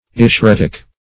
Search Result for " ischuretic" : The Collaborative International Dictionary of English v.0.48: Ischuretic \Is`chu*ret"ic\, a. Having the quality of relieving ischury.